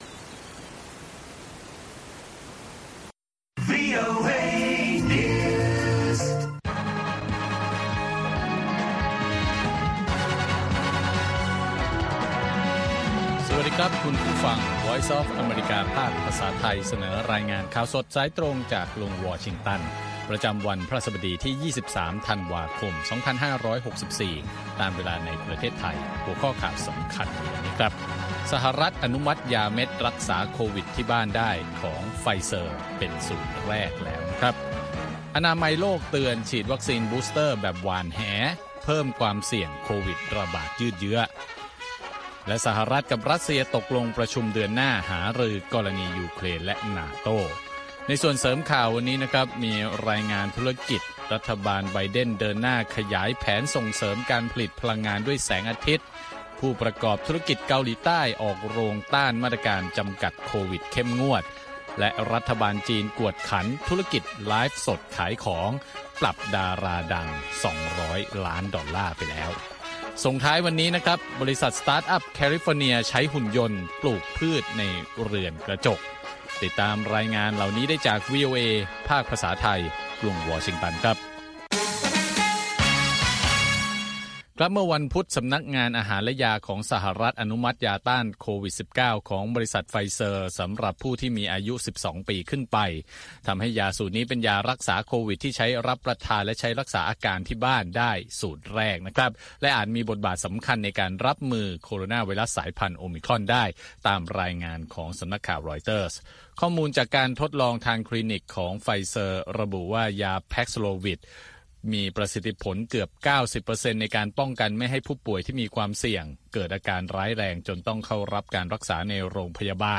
ข่าวสดสายตรงจากวีโอเอ ภาคภาษาไทย 8:30–9:00 น. ประจำวันพฤหัสบดีที่ 23 ธันวาคม2564 ตามเวลาในประเทศไทย